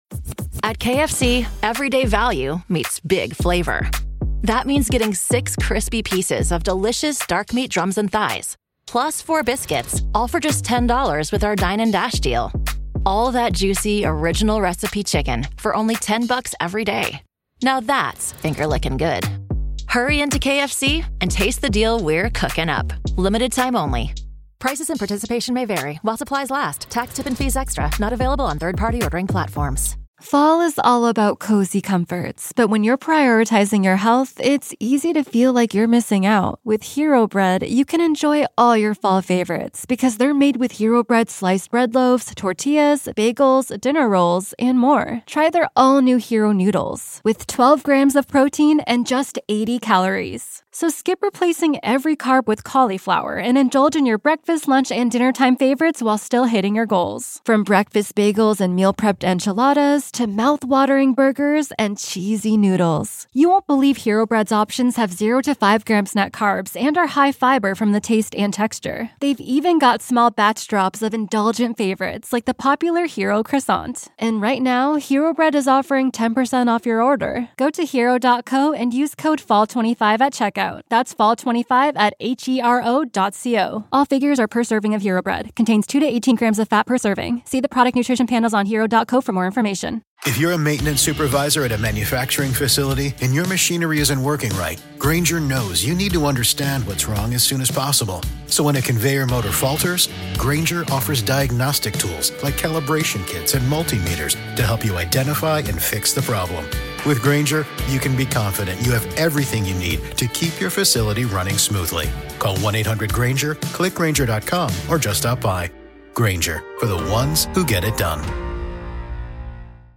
a young driver from the sunny state of Florida.